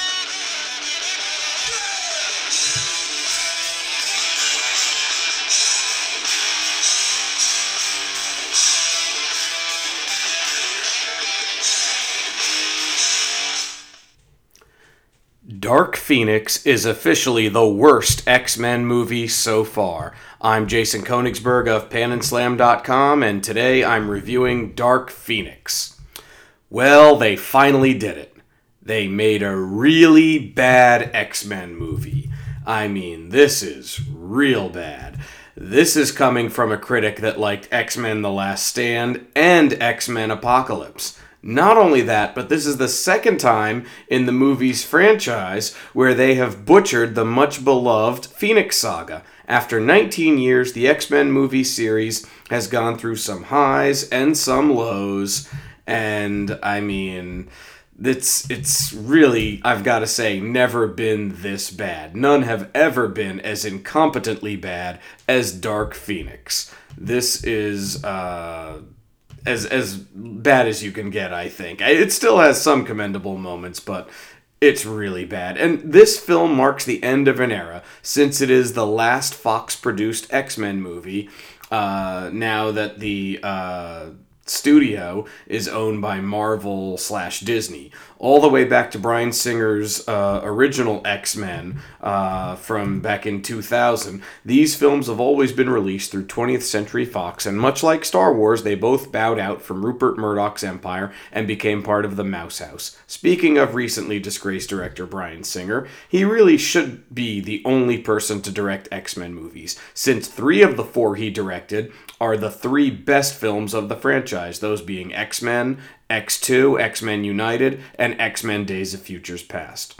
Movie Review: Dark Phoenix